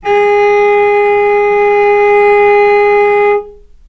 vc-G#4-mf.AIF